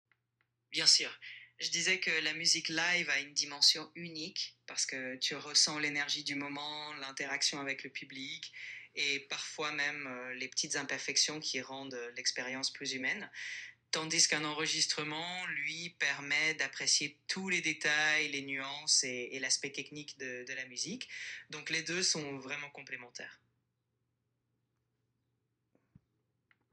Comparez les réponses vocales de
chatgpt-live.m4a